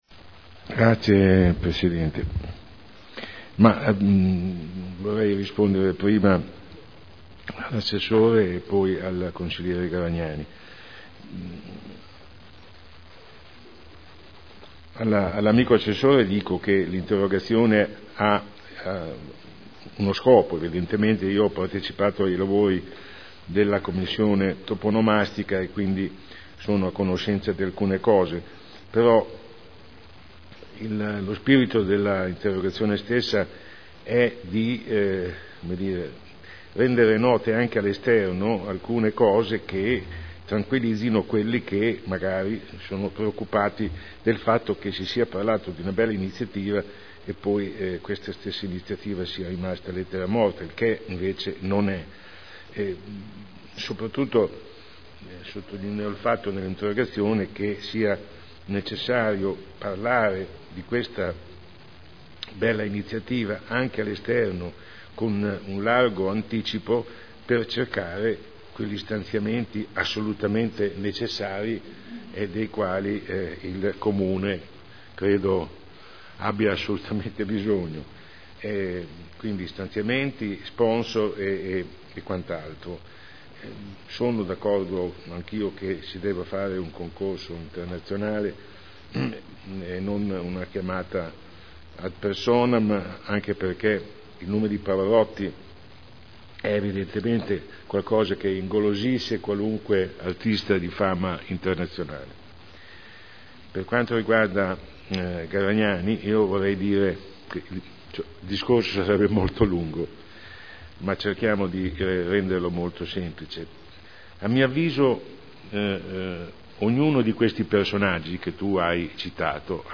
Sandro Bellei — Sito Audio Consiglio Comunale